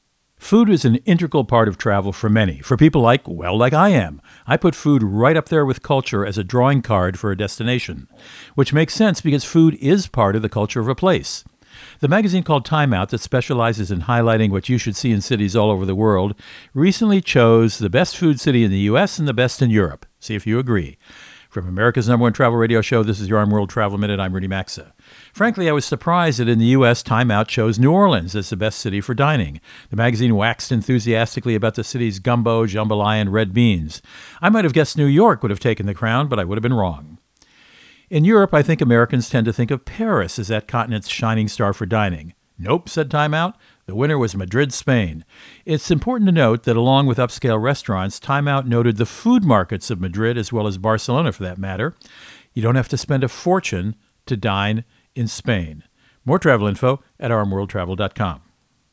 Co-Host Rudy Maxa | Hungry?  Thoughts on 2 “Foodie” Cities